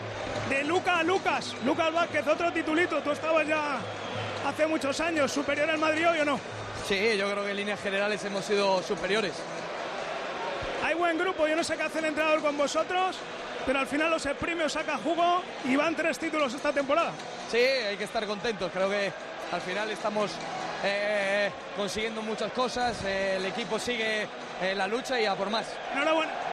AUDIO: Lucas habla en Tiempo de Juego del título ganado ante Osasuna.